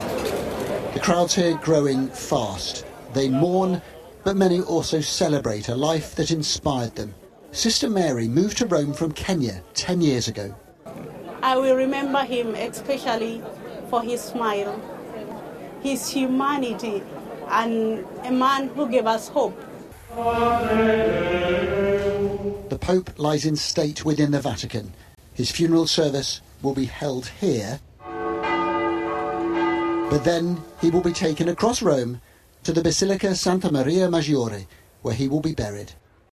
From Rome